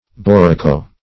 borracho - definition of borracho - synonyms, pronunciation, spelling from Free Dictionary Search Result for " borracho" : The Collaborative International Dictionary of English v.0.48: Borracho \Bor*rach"o\, n. See Borachio .